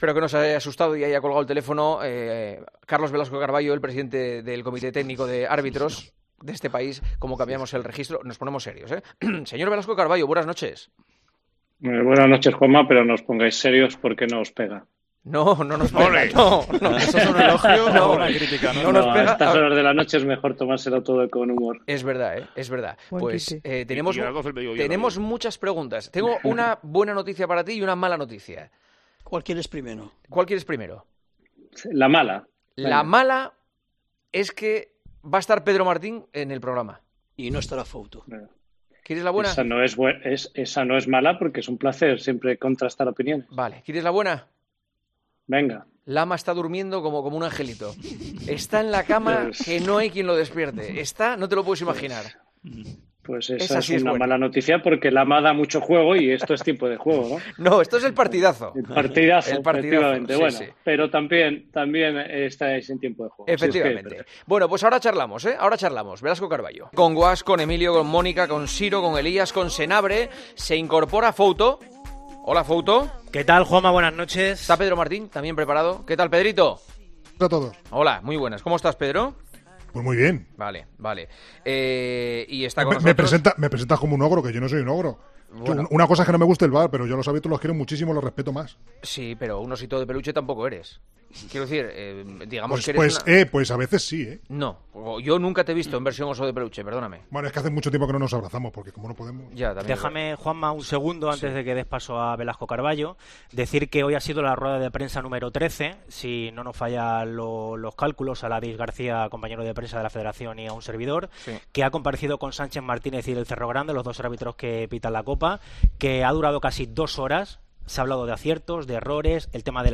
El presidente del Comité Técnico de Árbitros ha estado en El Partidazo de COPE valorando cómo está yendo la primera parte de la temporada para el...